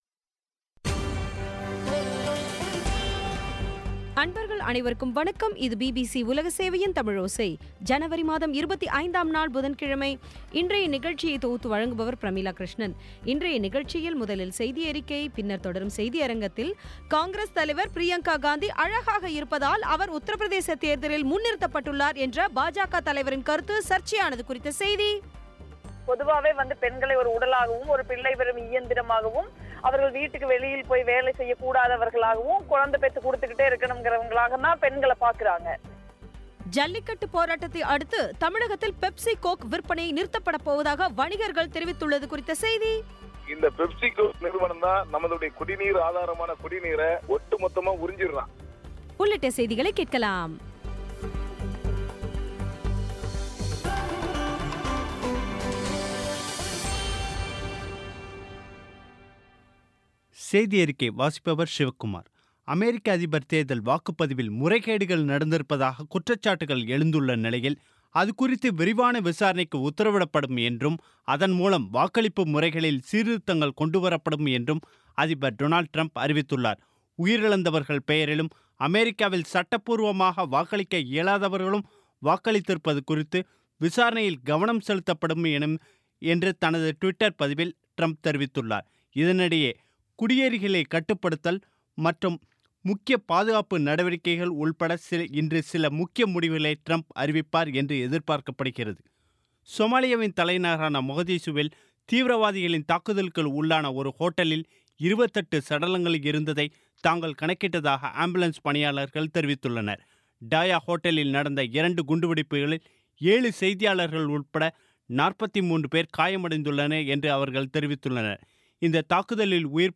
இன்றைய நிகழ்ச்சியில் முதலில் செய்தியறிக்கை, பின்னர் தொடரும் செய்தியரங்கில் காங்கிரஸ் தலைவர் பிரியங்கா காந்தி அழகாக இருப்பதால் உத்தர பிரதேச தேர்தலில் முன்னிறுத்தபட்டுள்ளார் என்ற பாஜக தலைவரின் கருத்து சர்ச்சையானது குறித்த செய்தி